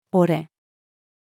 俺-female.mp3